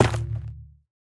Minecraft Version Minecraft Version latest Latest Release | Latest Snapshot latest / assets / minecraft / sounds / block / shroomlight / break1.ogg Compare With Compare With Latest Release | Latest Snapshot
break1.ogg